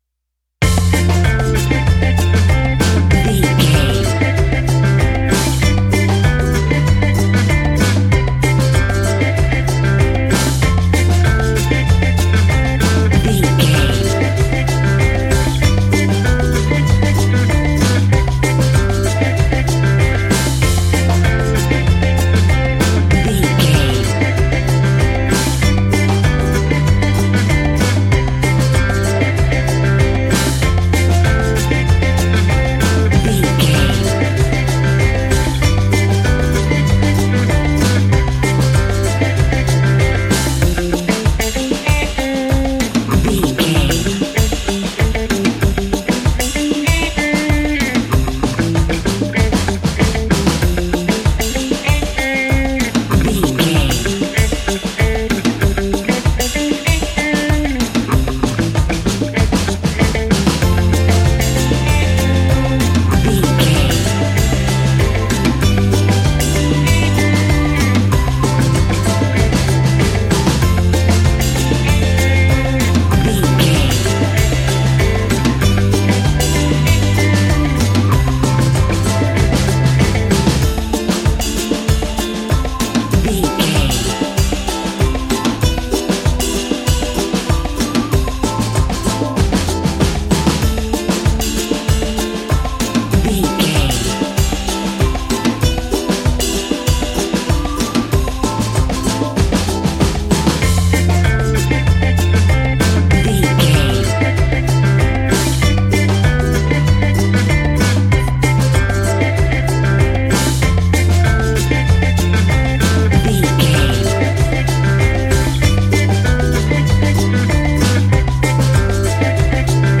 Uplifting
Ionian/Major
steelpan
World Music
drums
bass
brass
guitar